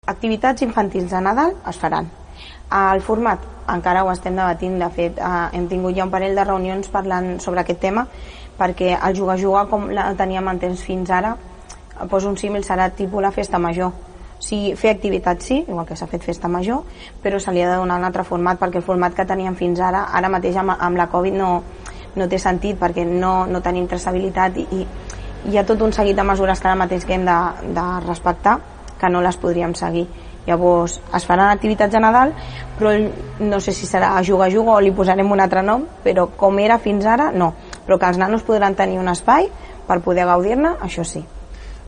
La regidora d’esports, Sònia González, ho explicava ahir en una entrevista al programa Assumptes Interns de Ràdio Palafolls.